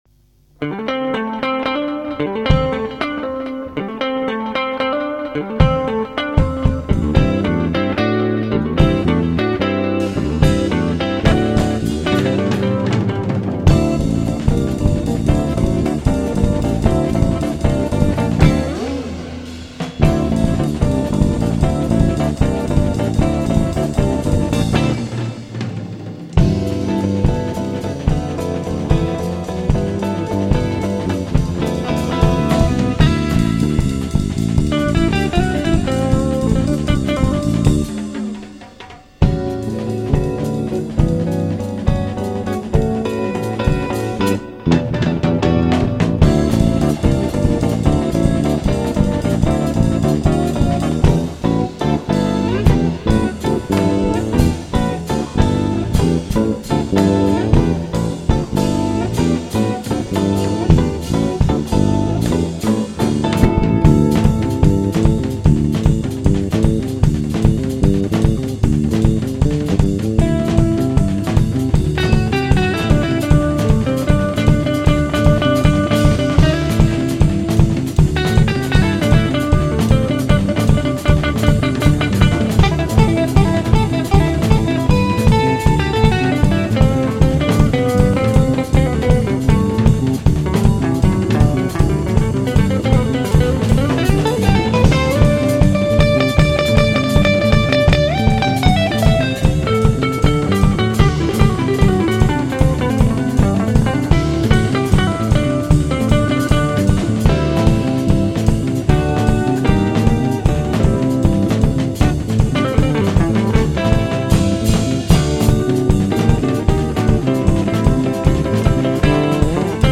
Guitar
Bass
Drumset